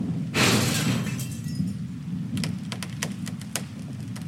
Car Crash
crash.mp3